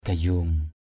/ɡ͡ɣa-zo:ŋ/ (d.) gáo (bằng quả bầu hay sọ dừa). balaok gayong b_l<K g_yU gáo.